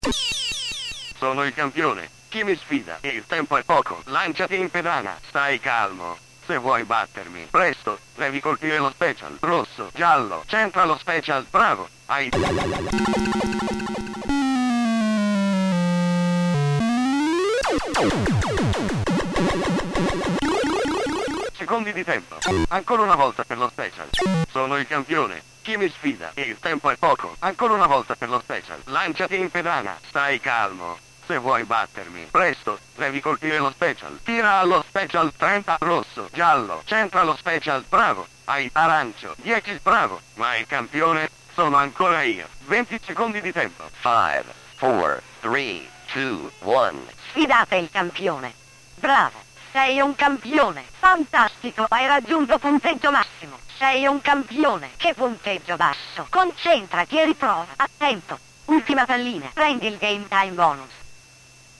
Pinballs and videogames audio samples
Sampled sounds and voices from sound board self-test
pinchamp82soundtest.mp3